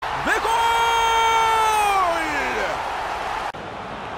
Play, download and share goolllll original sound button!!!!
goolllll.mp3